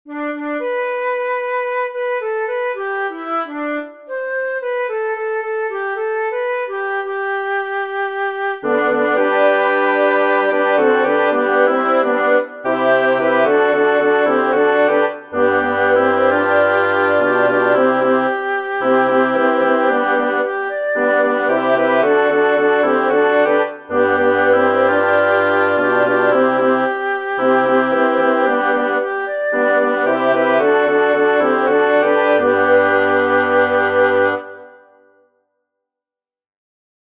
This version is the same arrangement as the one in the PDF.
Listen to the harmony on this MP3 file…